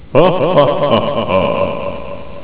evillaugh.au